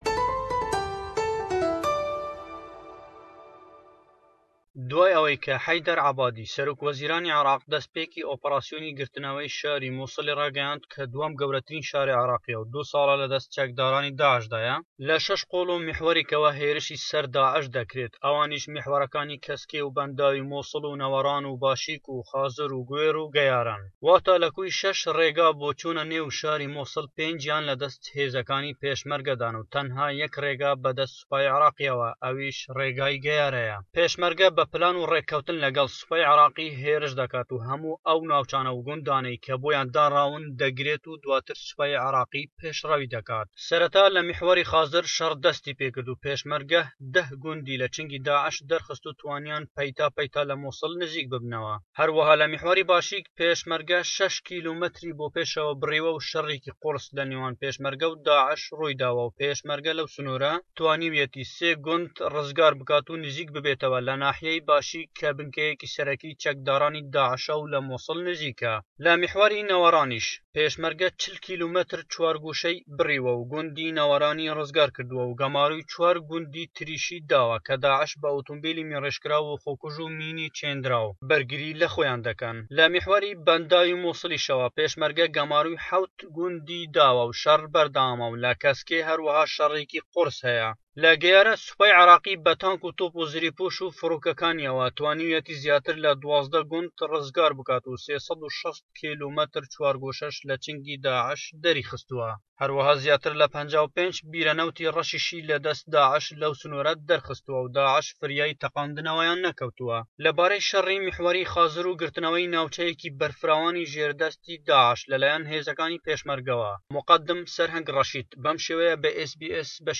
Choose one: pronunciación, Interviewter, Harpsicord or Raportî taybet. Raportî taybet